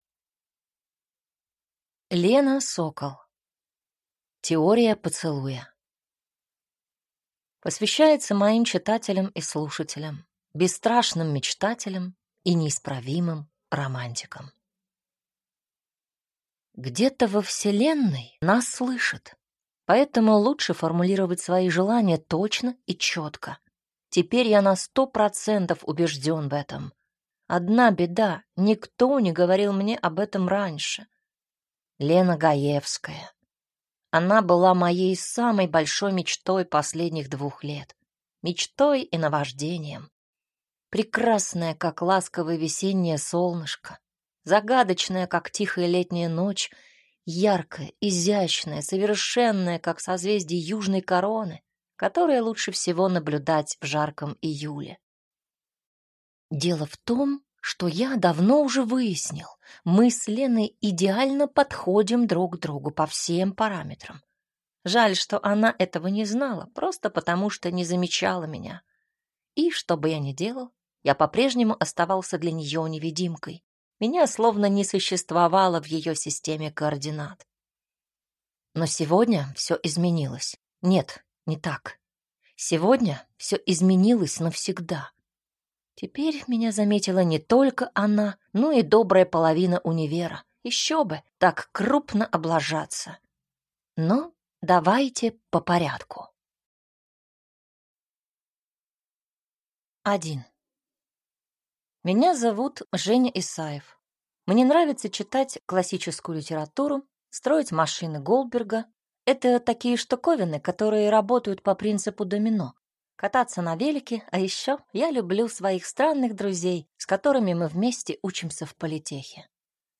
Аудиокнига Теория поцелуя | Библиотека аудиокниг